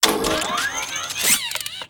droneactivate.ogg